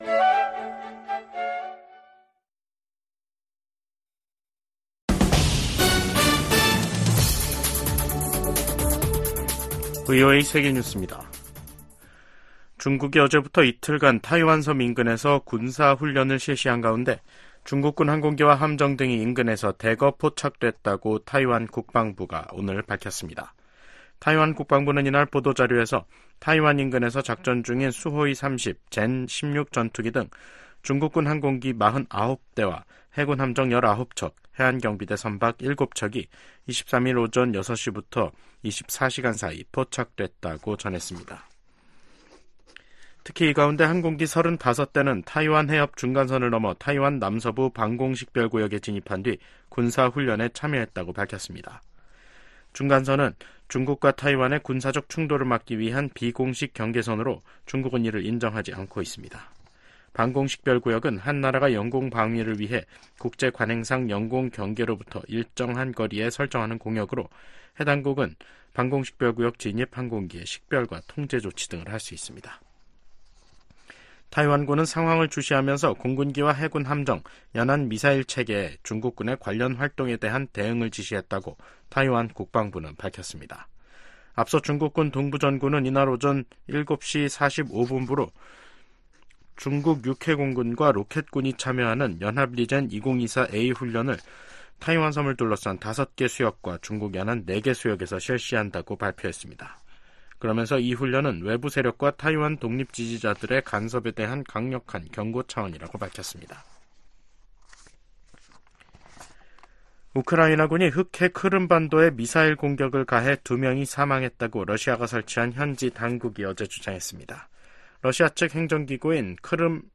VOA 한국어 간판 뉴스 프로그램 '뉴스 투데이', 2024년 5월 24일 2부 방송입니다. 미 국무부는 미국과 그 동맹들이 역내 긴장을 고조시킨다는 러시아의 주장을 일축하고, 긴장 고조의 원인은 북한에 있다고 반박했습니다. 2025회계연도 미국 국방수권법안이 하원 군사위원회를 통과했습니다. 주한미군 규모를 현 수준으로 유지해야 한다는 내용도 들어있습니다.